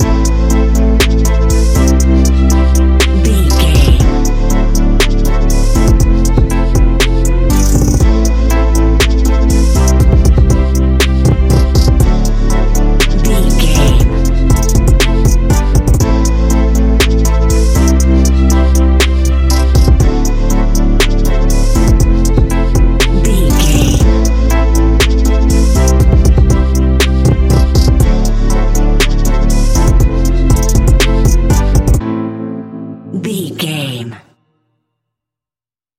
Ionian/Major
A♭
laid back
Lounge
sparse
new age
chilled electronica
ambient
atmospheric
morphing